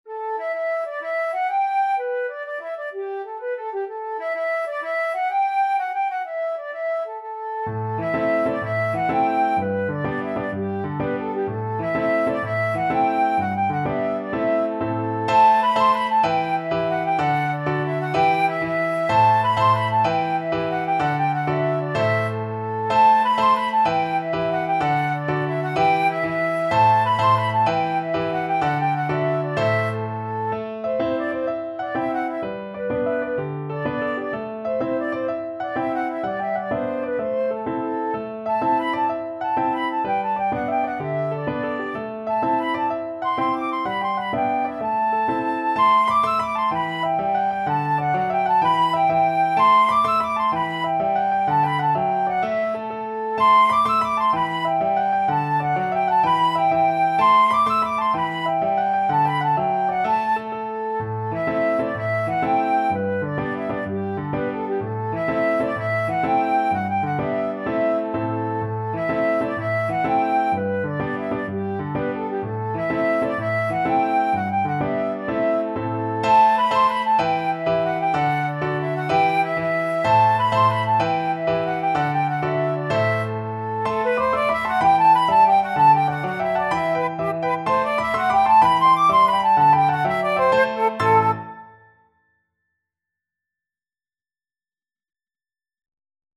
Flute
12/8 (View more 12/8 Music)
E minor (Sounding Pitch) (View more E minor Music for Flute )
Fast .=c.126
Irish